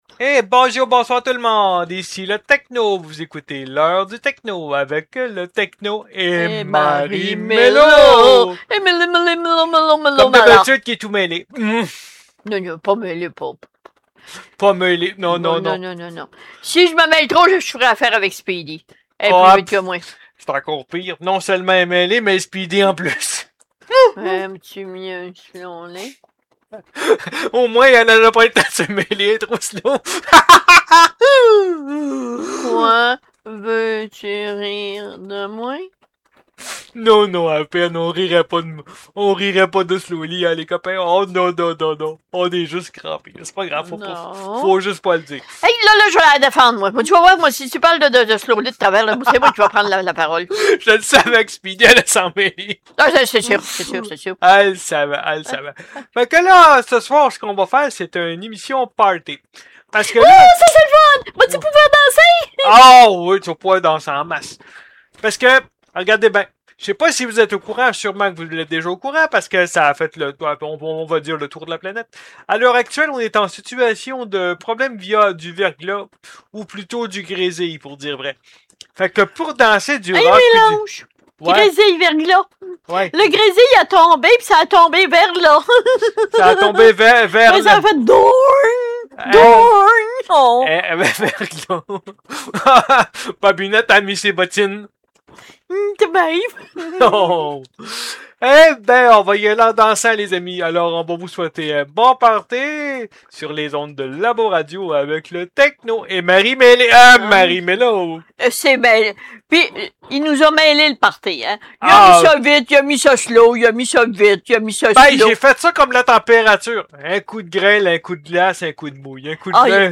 rediffusion